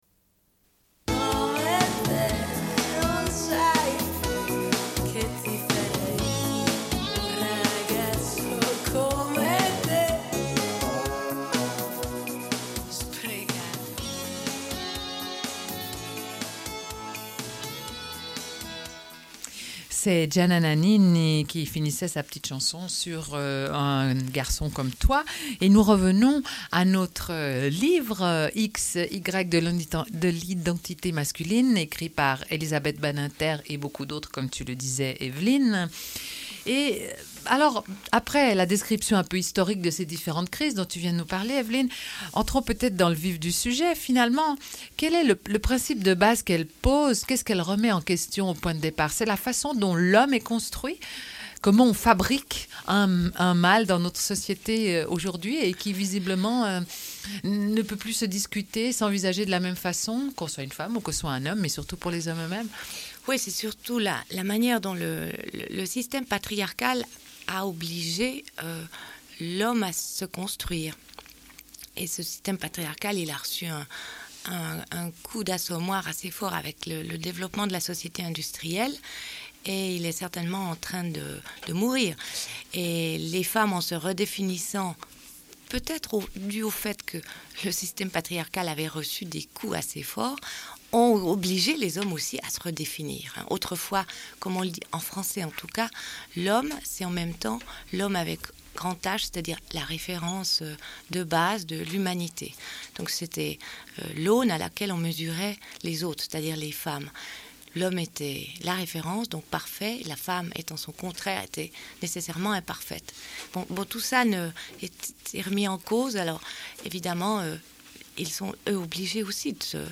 Une cassette audio, face A31:25